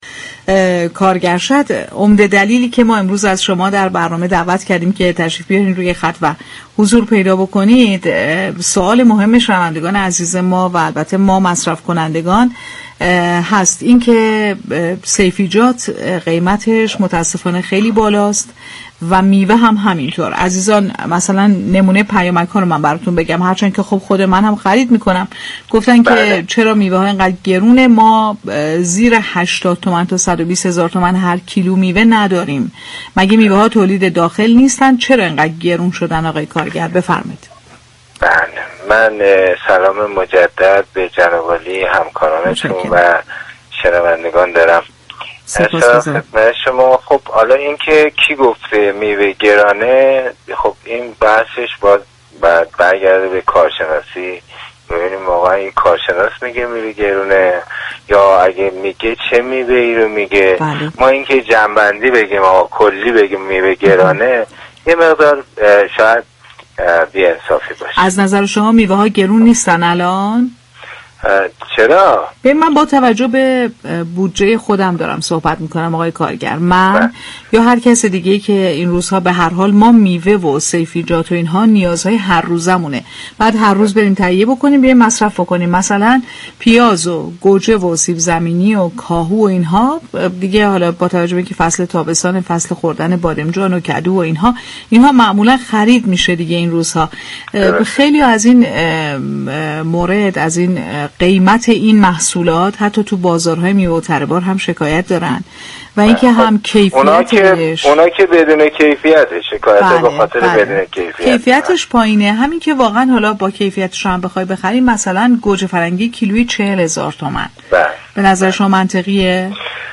در گفت و گو با «بازار تهران»